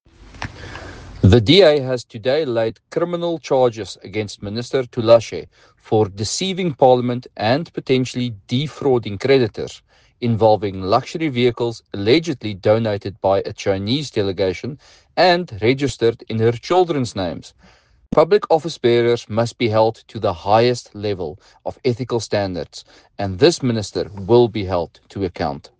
Afrikaans soundbite by Jan de Villiers MP.